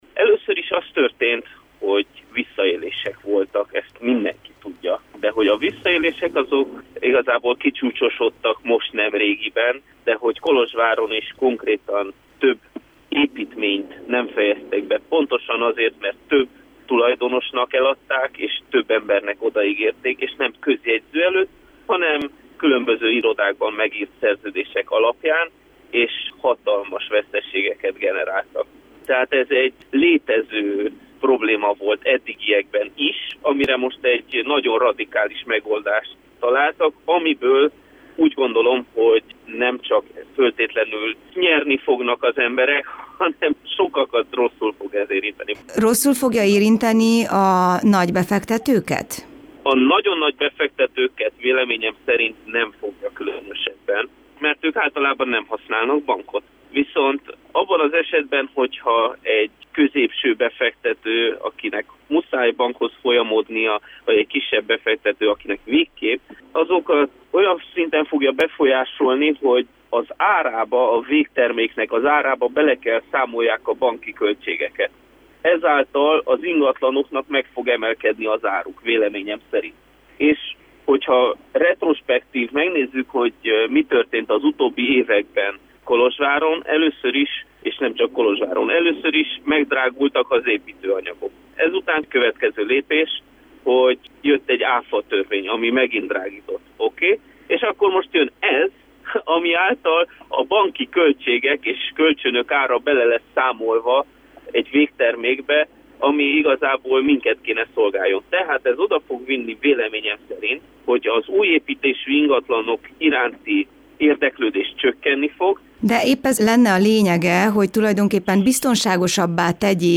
A rádiónknak nyilatkozó ingatlanügynök azt mondja, a jogszabály megfelelő védelmet biztosít, ugyanakkor gyanítja, hogy a kisebb vagy közepes befektetők nehezebben tudnak majd építkezni és ez az ingatlanok árában csapódik majd le.